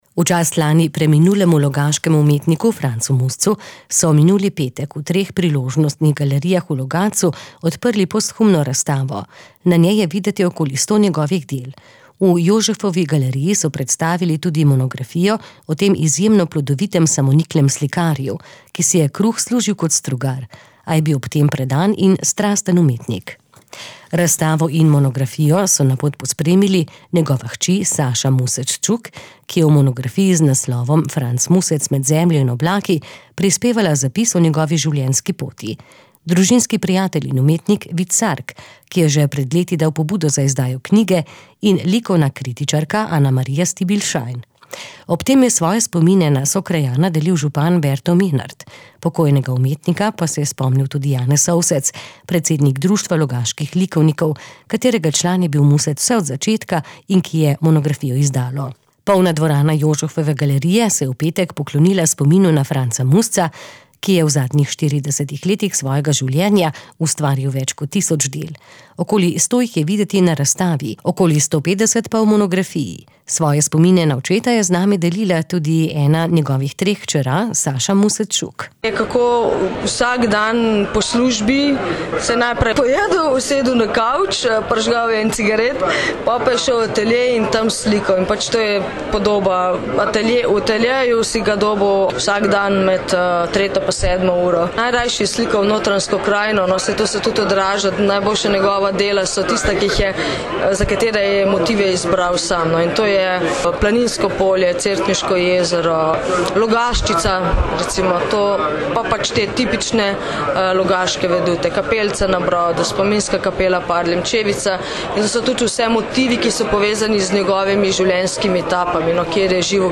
Vse tri smo povabili pred mikrofon.